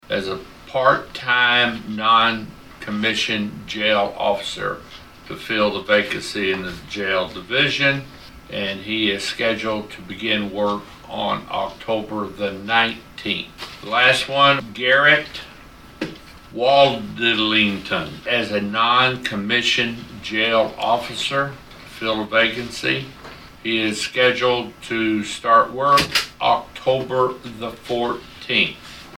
During a recent meeting of the Saline County Commission, commissioners were asked to give their approval to several new hires for the sheriff’s department.